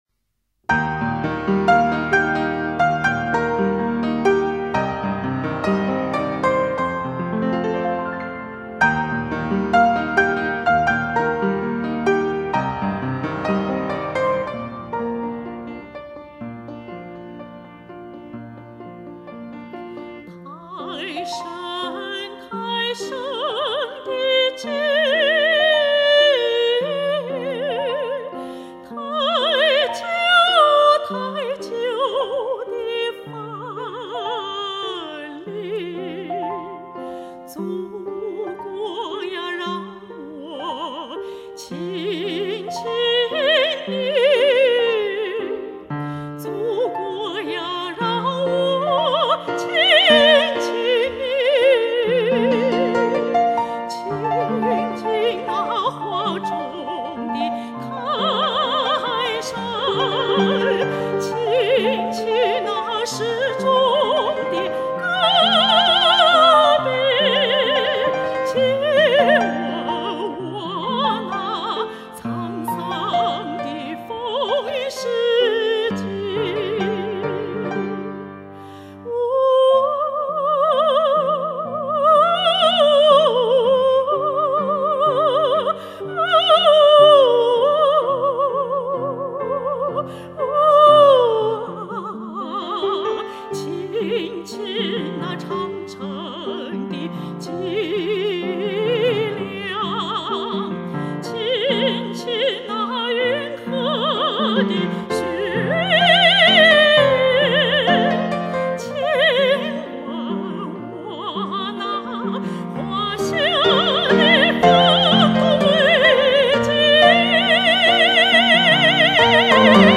原创歌曲《亲吻祖国》美声版（词：雷子明 曲：南利华） 激动社区，陪你一起慢慢变老！